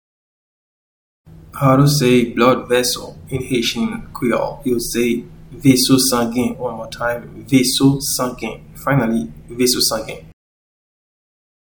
Pronunciation:
Blood-vessel-in-Haitian-Creole-Veso-sangen-1.mp3